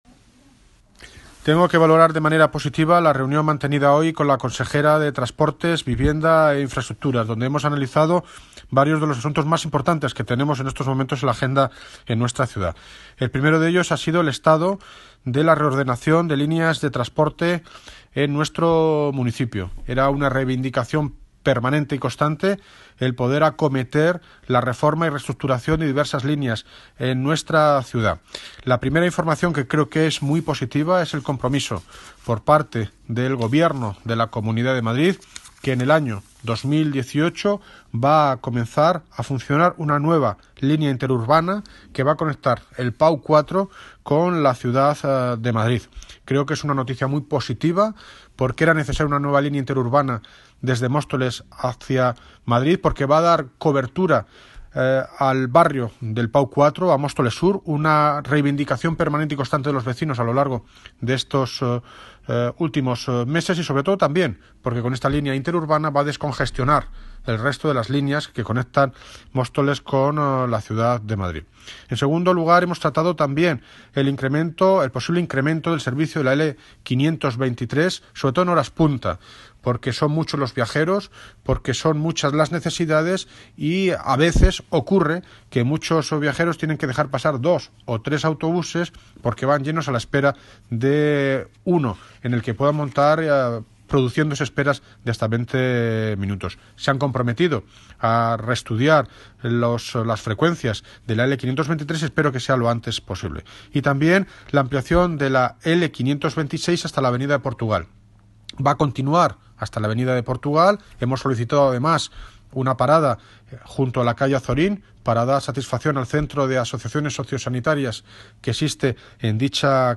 Audio David Lucas (Alcalde de Móstoles) Sobre Reunión Consejería de Transportes